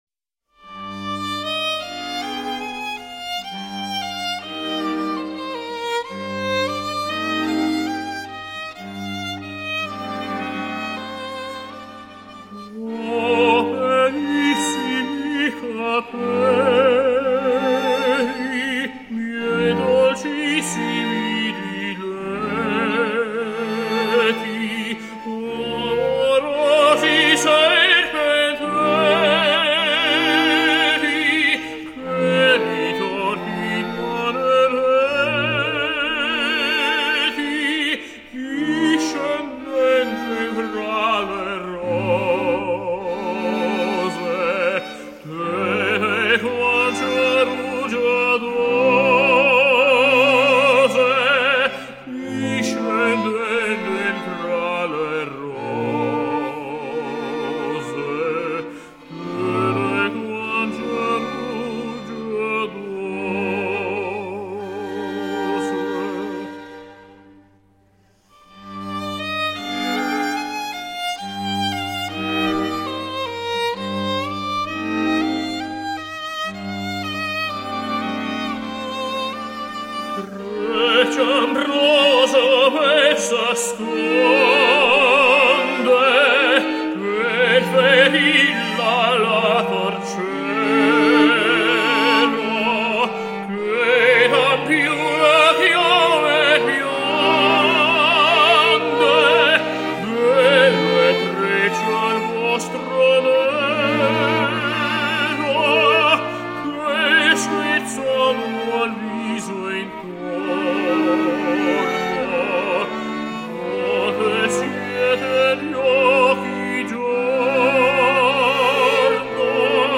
first violin
second violin
viola
cello
tenor